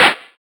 Snares